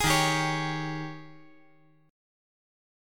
Eb11 Chord
Listen to Eb11 strummed